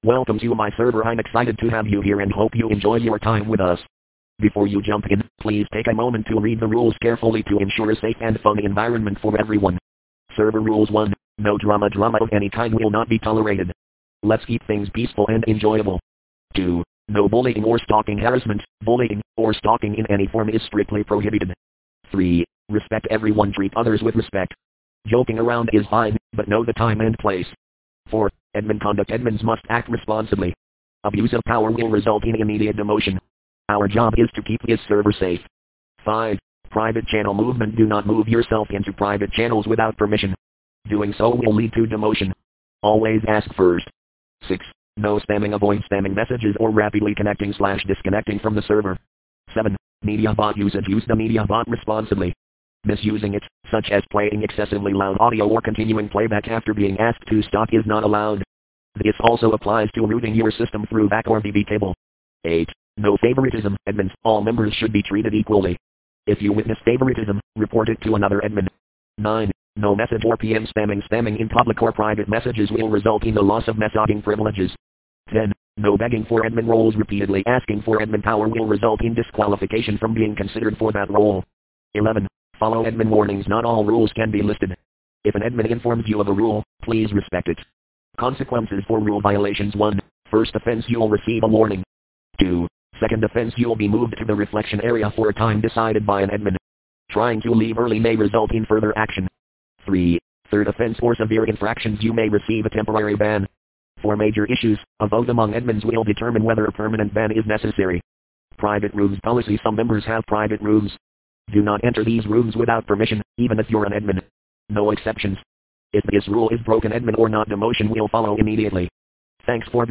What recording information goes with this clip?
Okay, so this is the audio, in the same bite rate, but not distorted.